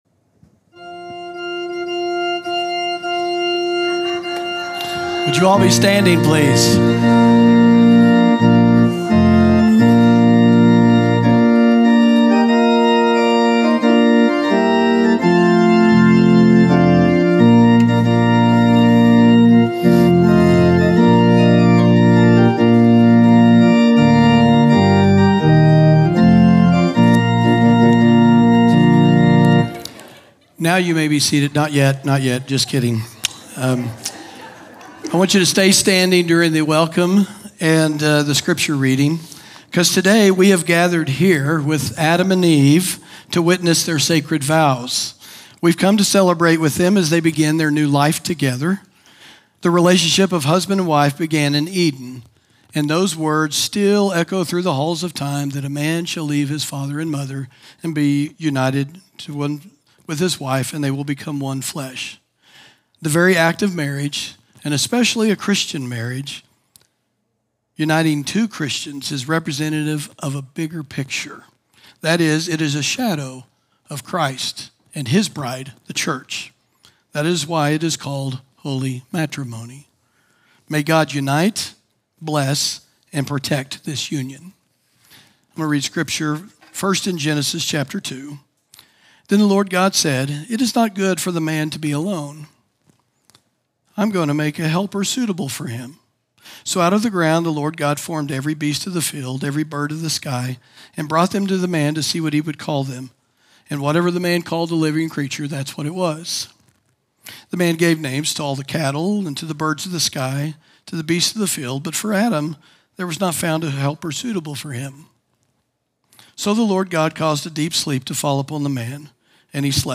sermon audio 0601.mp3